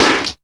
TEAR CLICK.wav